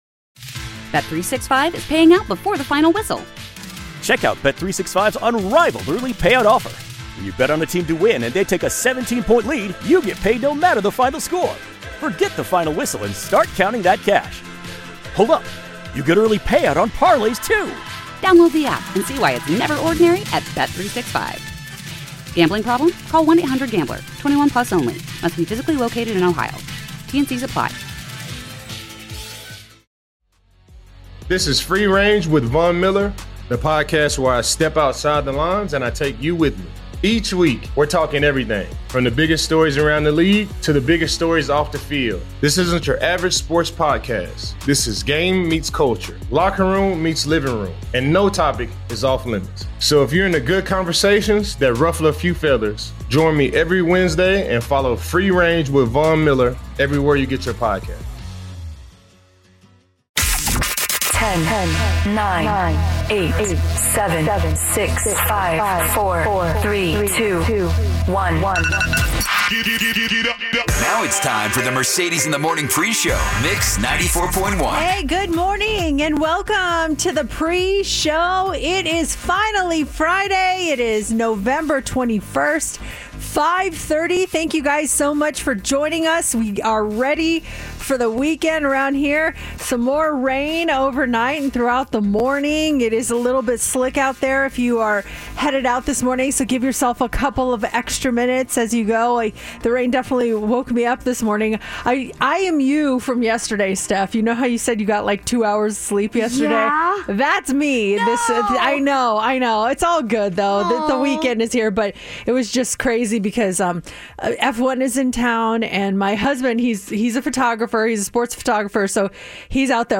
Purpose of the Interview